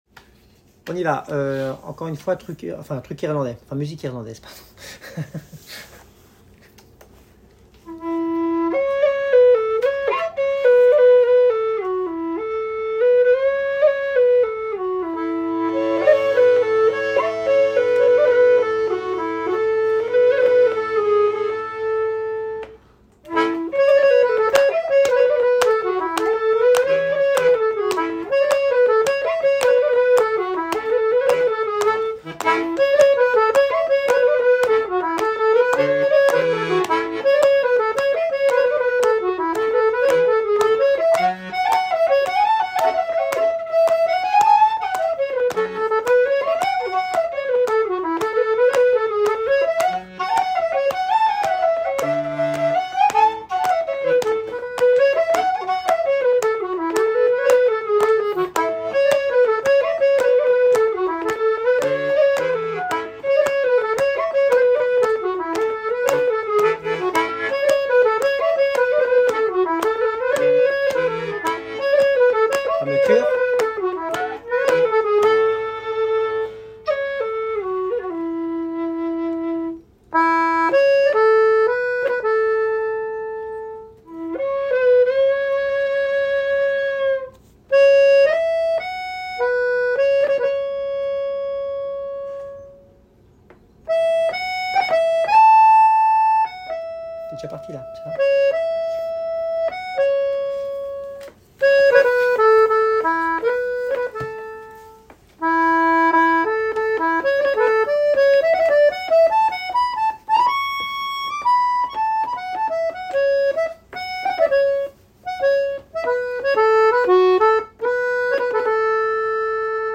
2_27 – jigs irlandaises sans guitare (audio):
2_27 - jigs irlandaises sans guitare.mp3